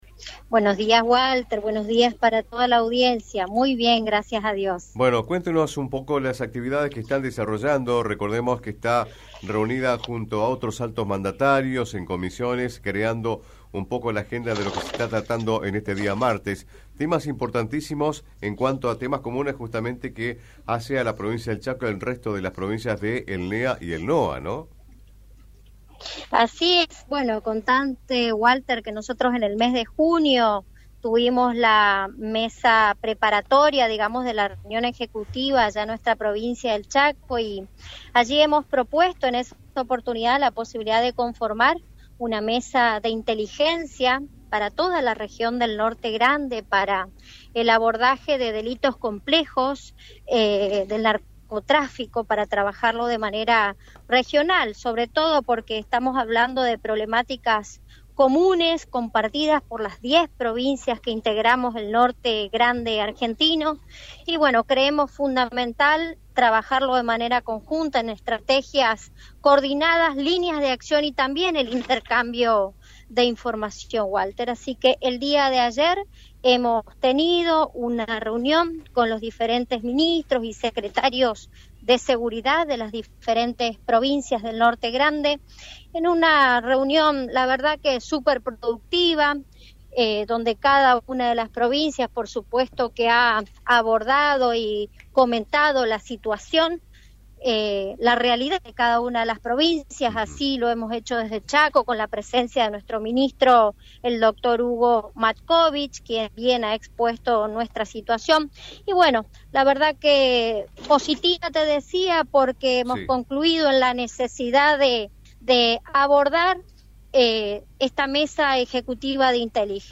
«Estamos hablando de problemáticas compartidas por las diez provincias del Norte Grande. Creemos fundamental trabajar de manera conjunta, con estrategias coordinadas, líneas de acción comunes y el intercambio permanente de información», remarcó la vicegobernadora en diálogo con Radio Provincia del Chaco.